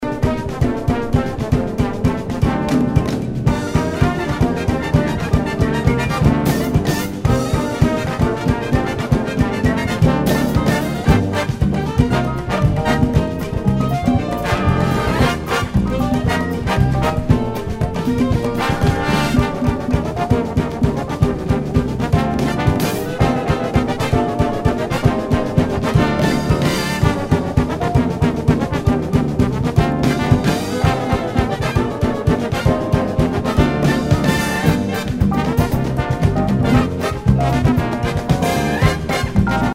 ラテンサンバチューンのB-2他、ビッグ・バンド編成ならではのクラシカルな曲からフュージョン系までを全８曲収録!!
Tag       JAZZ OTHER